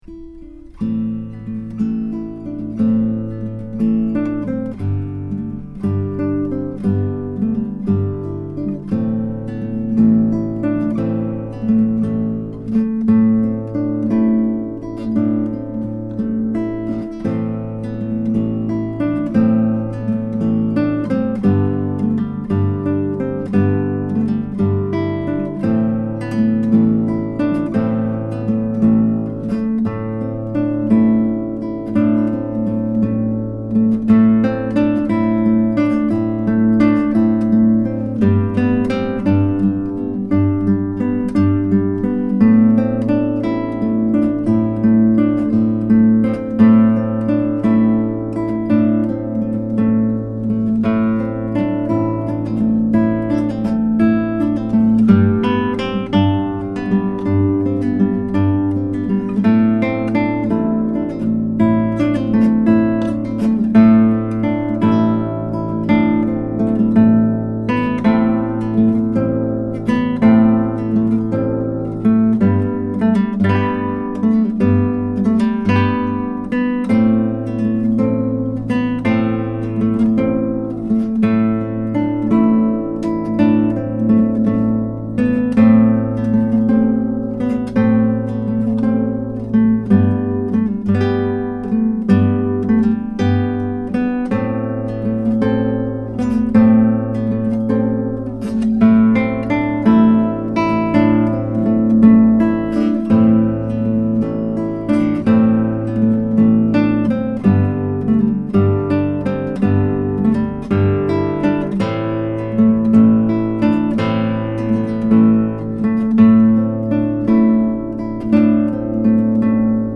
10-String Guitar
The guitar has amazing sympathetic resonance and sustain, as well as good power and projection, beautiful bass responce, a very even response across the registers. These MP3 files have no compression, EQ or reverb -- just straight signal, tracked through a Wunder CM7GT multi-pattern tube mic, into a Presonus ADL 600 preamp into a Rosetta 200 A/D converter.
Irish, Ca. 1200)